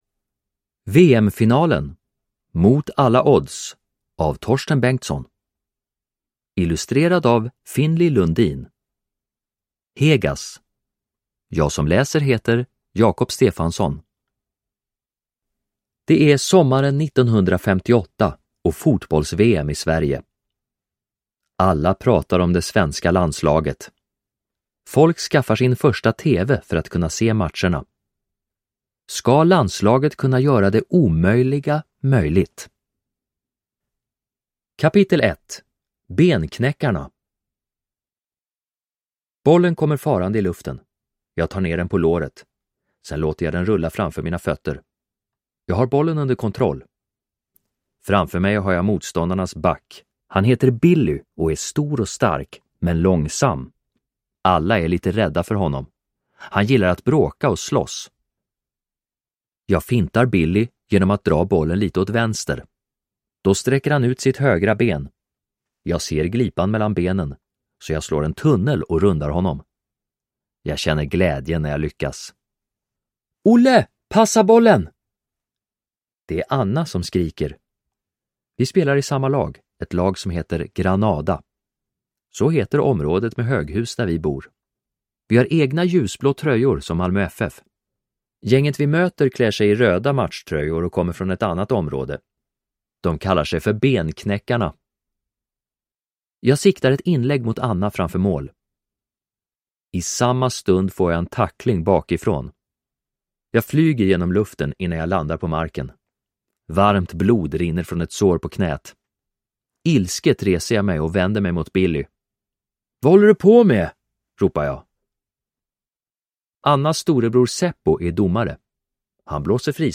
Ljudbok